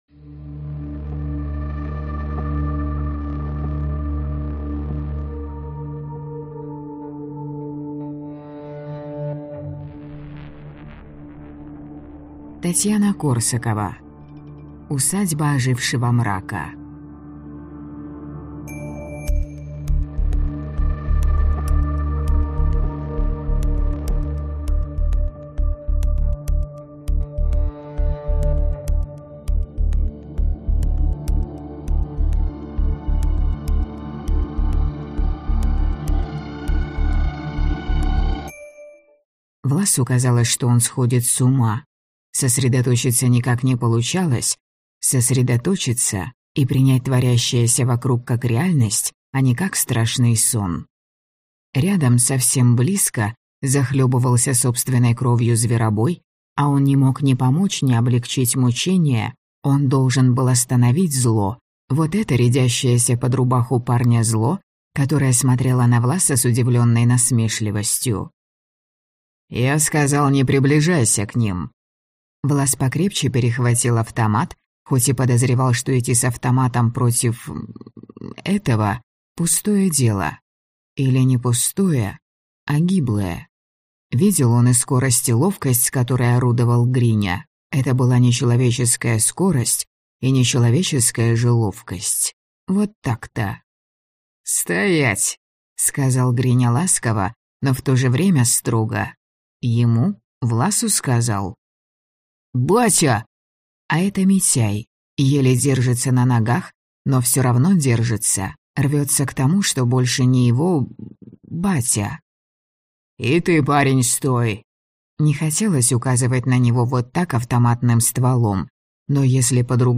Аудиокнига Усадьба ожившего мрака | Библиотека аудиокниг